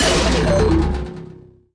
Npc Robot Powerdown Sound Effect
Download a high-quality npc robot powerdown sound effect.
npc-robot-powerdown.mp3